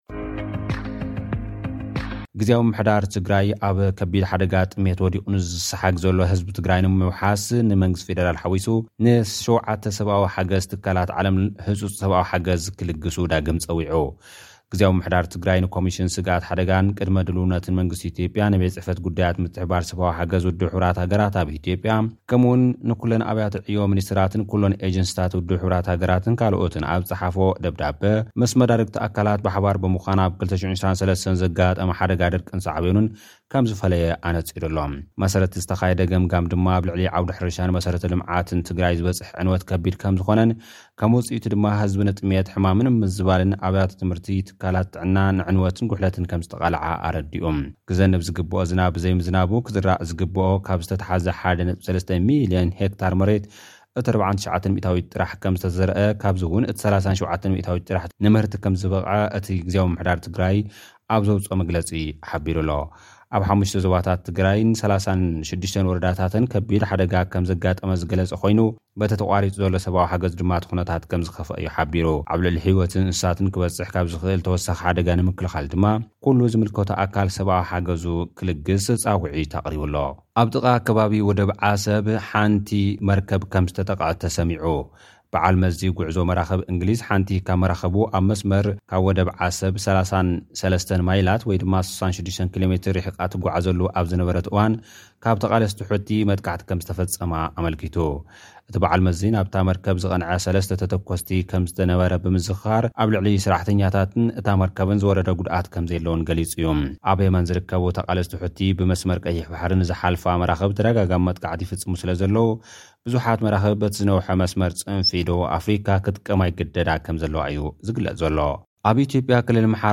ኣብ ወደብ ዓሰብ ናይ ቀረባ ርሕቀት ናብ መርከብ ዘቕነዐ ነታጒ ተተኲሱ። (ሓጸርቲ ጸብጻብ ልኡኽና)